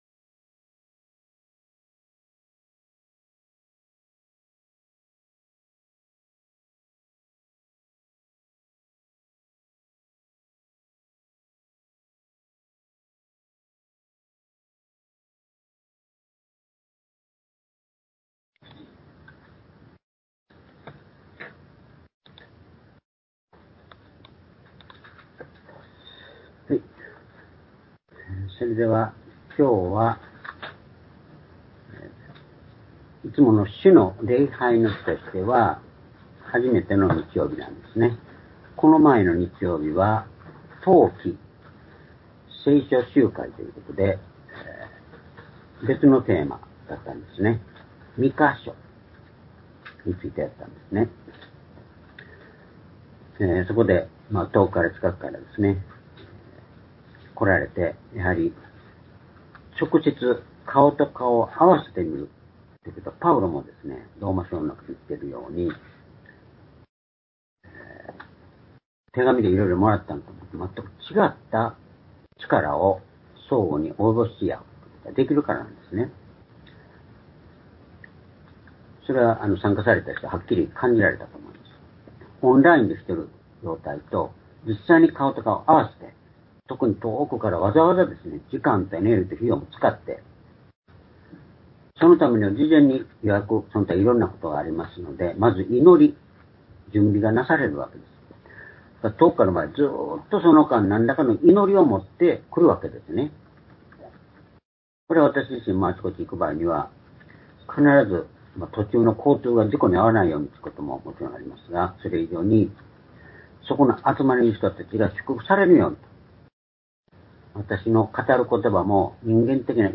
「日々新たにされて」コロサイ書3章９節～１１節－２０２５年１月１２日（主日礼拝）